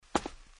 Warcorrespondents / Assets / 音效 / 走&跑 / 走路2.mp3
走路2.mp3